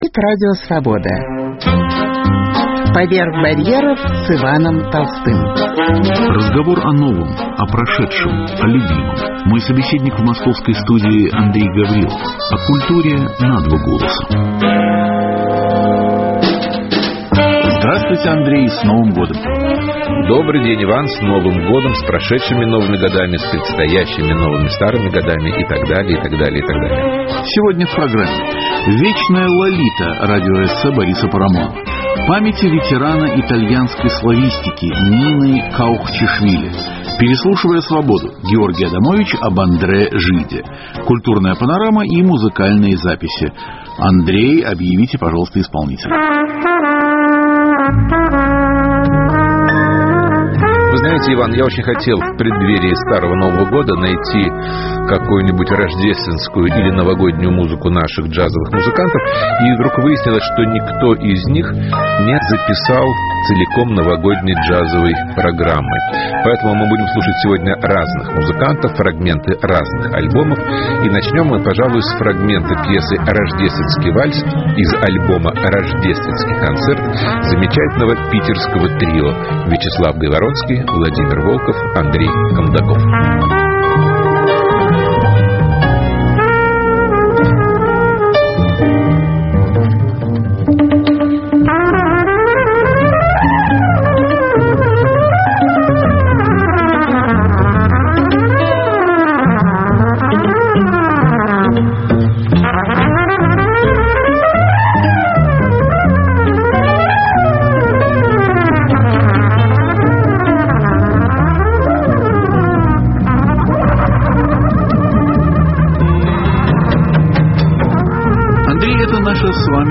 Переслушивая Свободу: Георгий Адамович об Андре Жиде. Культурная панорама и музыкальные записи.